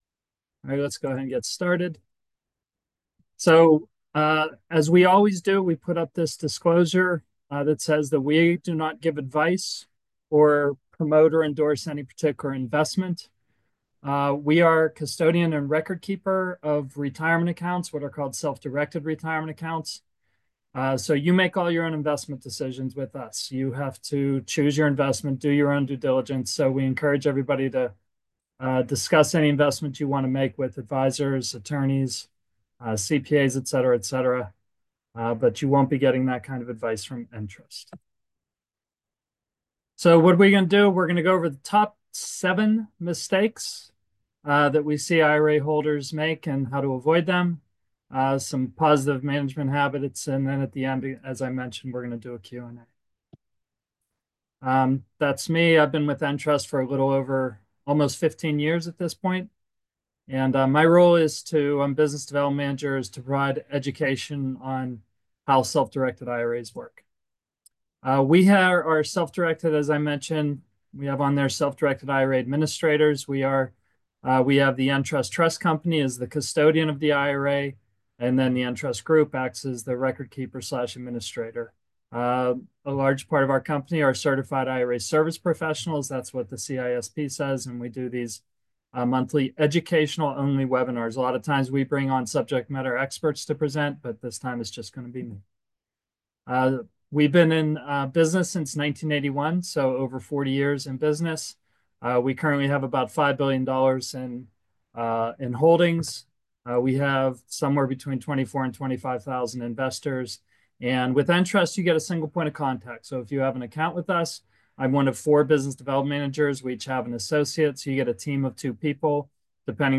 Webinar December 2024 Audio Replay.m4a